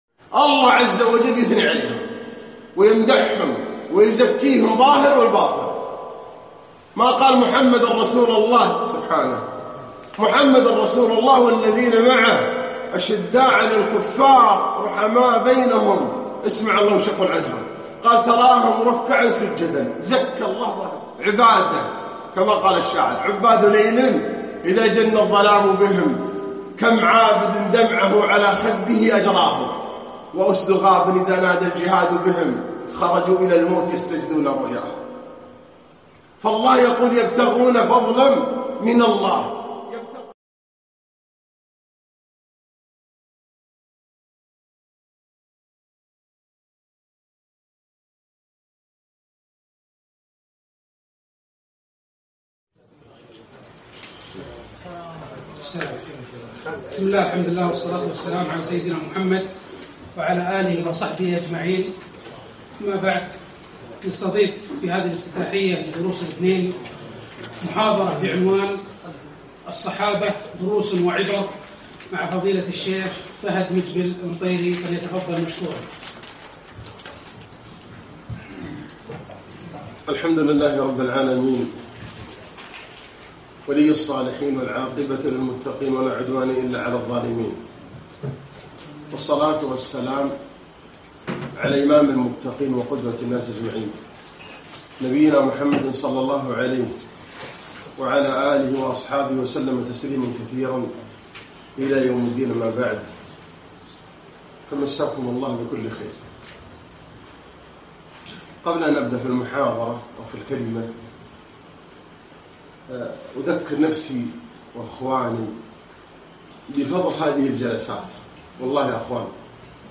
محاضرة - الصحابة رضي الله عنهم دروس و عبر